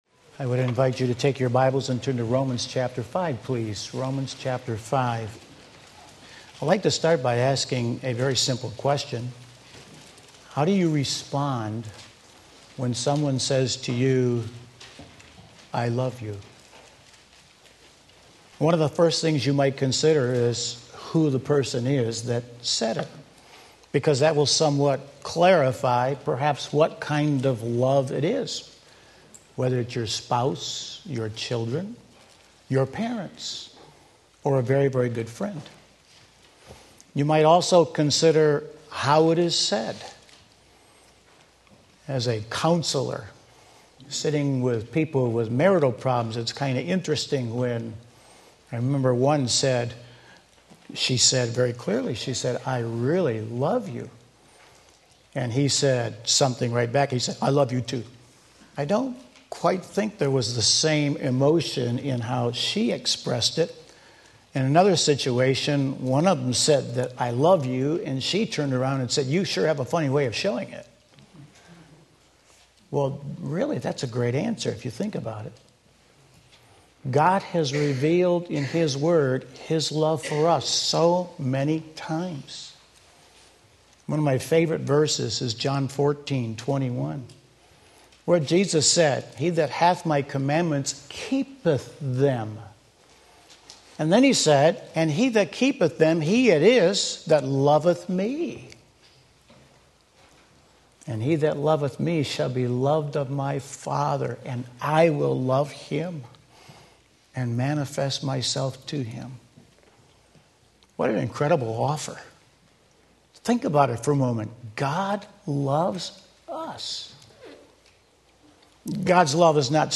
Sermon Link
God’s Wonderful Love Romans 5:8 Sunday Morning Service